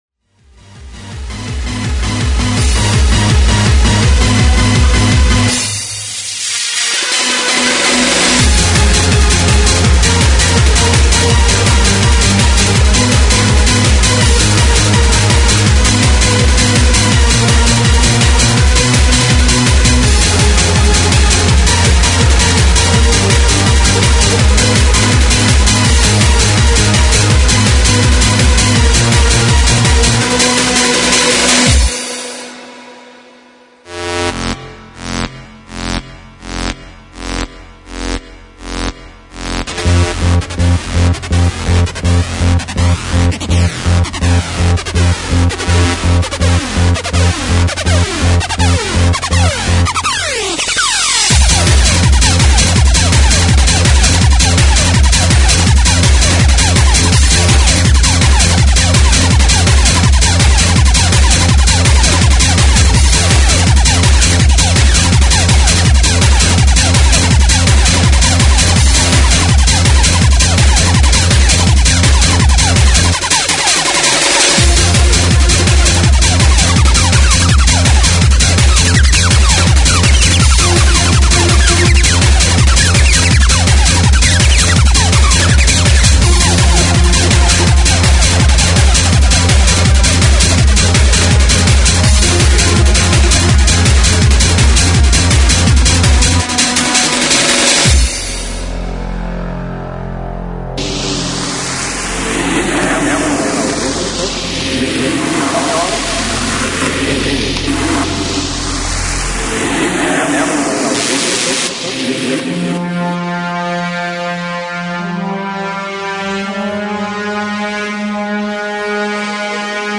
Freeform/Finrg/Hardcore